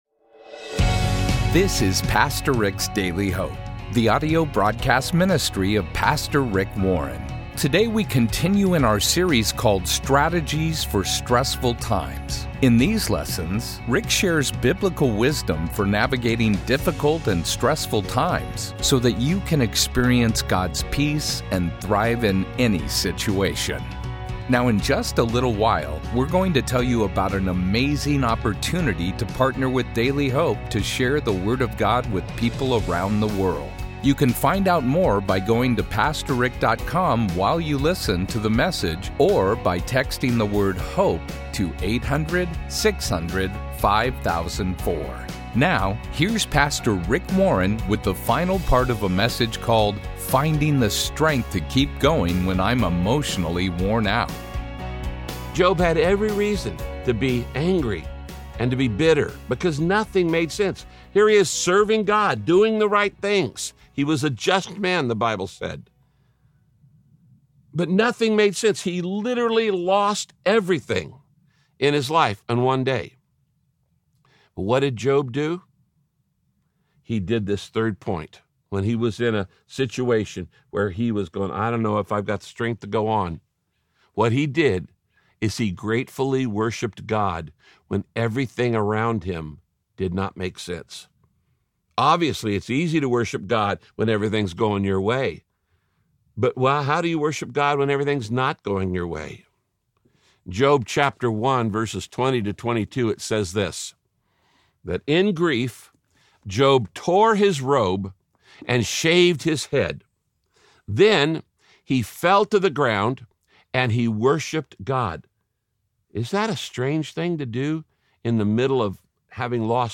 Radio Broadcast Finding the Strength to Keep Going When I’m Emotionally Worn Out – Part 3 Many of us tend to focus on our problems instead of on God.